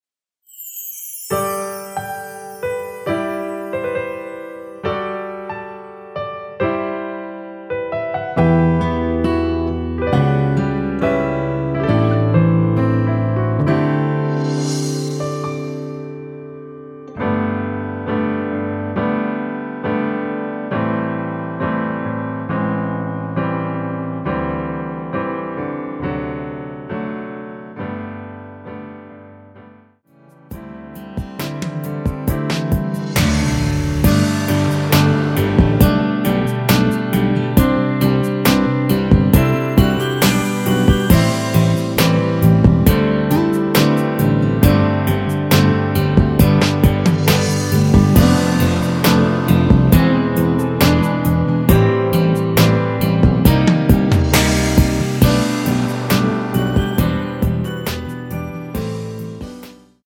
◈ 곡명 옆 (-1)은 반음 내림, (+1)은 반음 올림 입니다.
앞부분30초, 뒷부분30초씩 편집해서 올려 드리고 있습니다.
위처럼 미리듣기를 만들어서 그렇습니다.